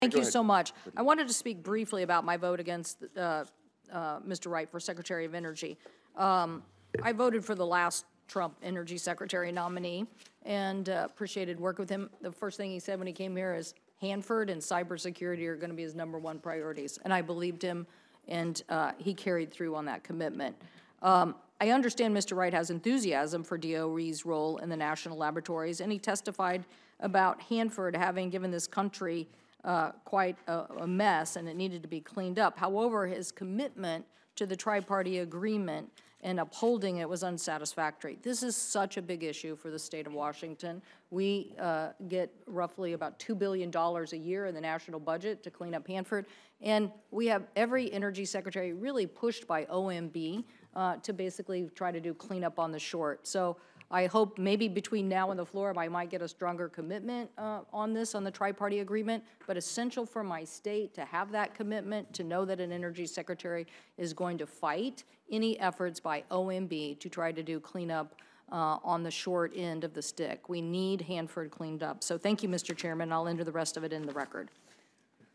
In a committee markup today, Sen. Cantwell cited an exchange with Wright during a hearing last week - when she questioned him on whether he'd pledge to uphold the newly negotiated agreement between the State of Washington, DOE, and the U.S. Environmental Protection Agency (EPA) that directs cleanup of the Hanford nuclear site in the Tri-Cities, and he fell short of giving a concrete answer.